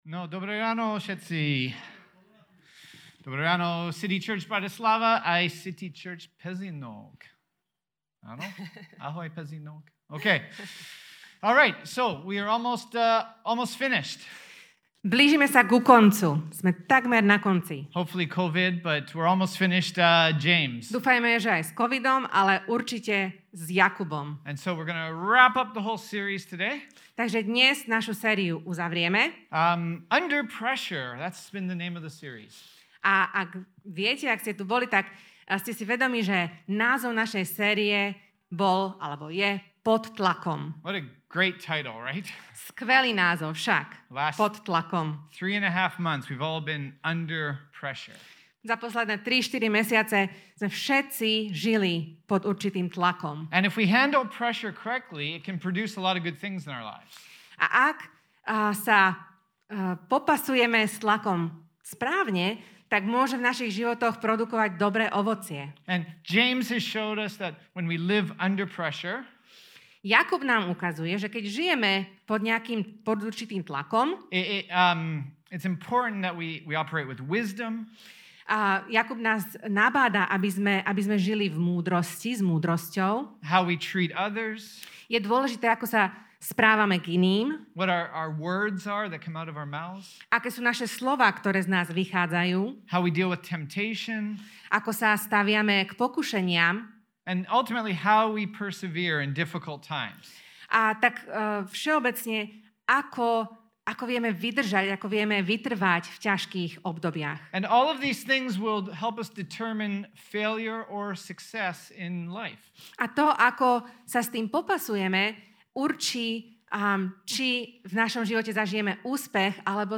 Ako ľudia uponáhľanej doby sme veľmi prirodzene netrpezliví. V tejto kázni sa pozrieme na to čo o trpezlivosti hovorí Jakub vo svojom liste a čo nám chce poradiť.